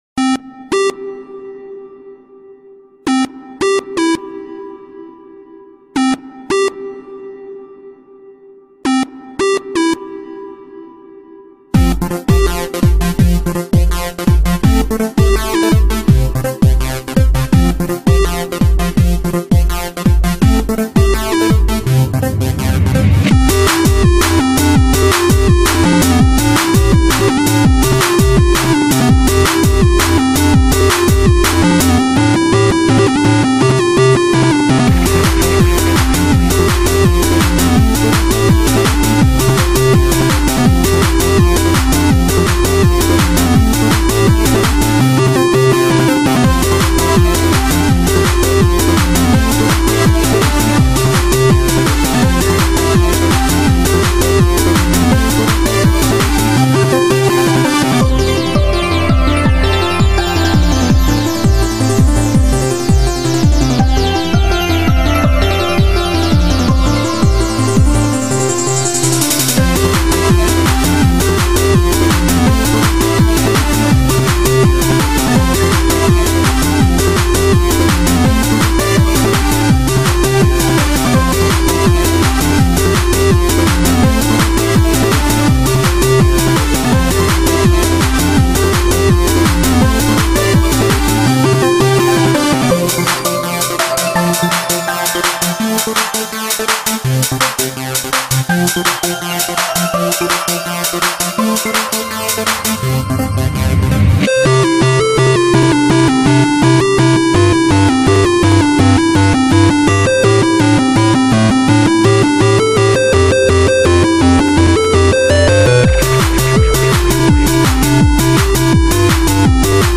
This was a sorta chiptune song I did in Fl studio..